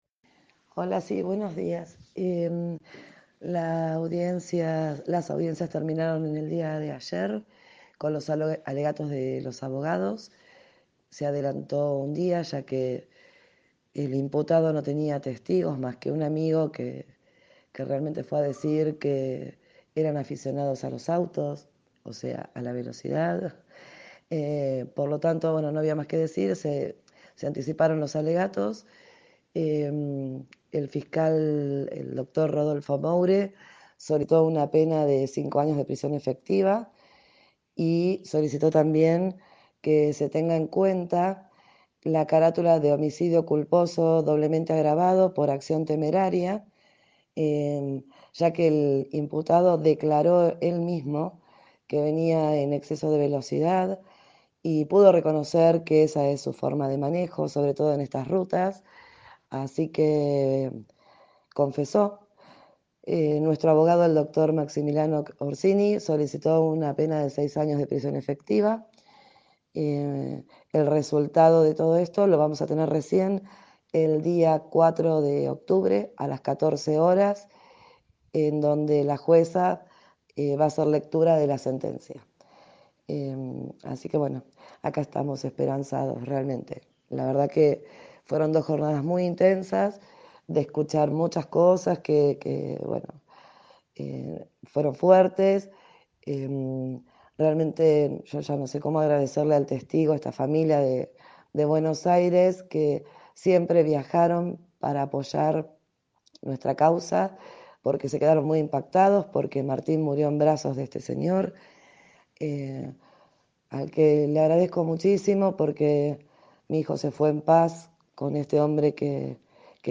dialogó al respecto con Bien Despiertos, programa emitido de 7 a 9, por Radio de la Azotea